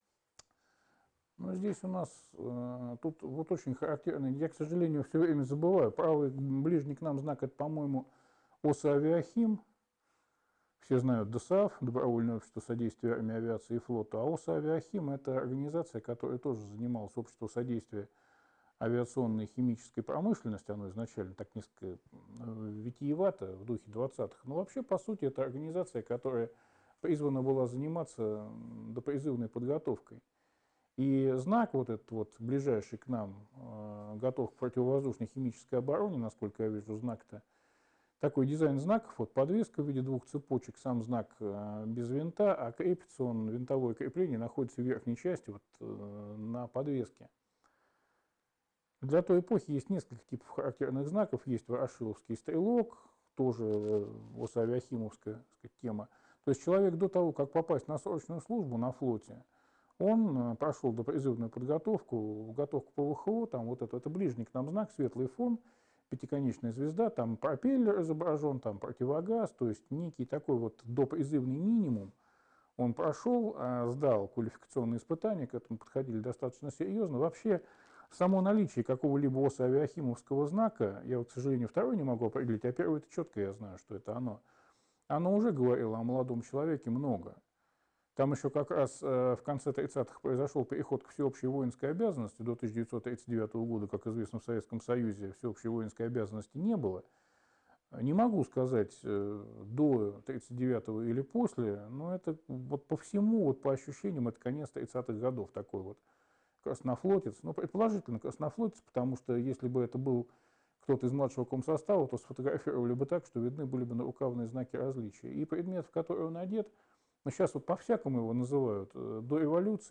3. «Сеансы связи» в Доме Белявского